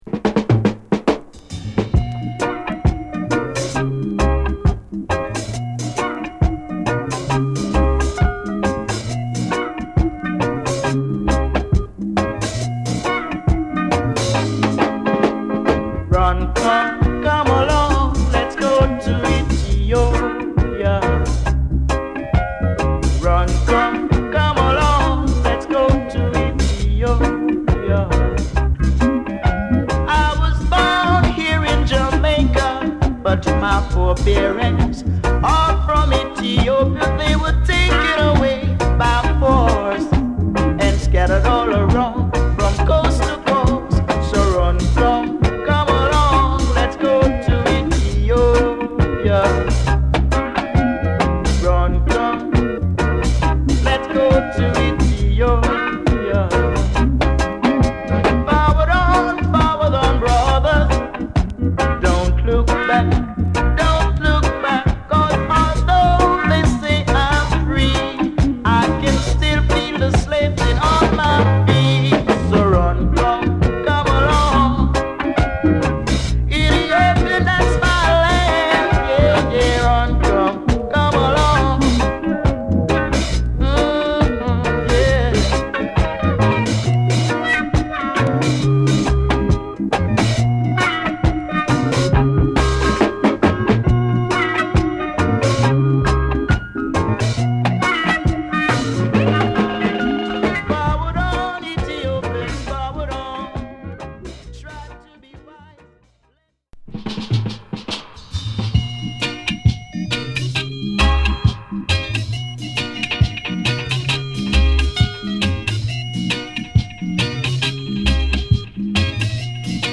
7inch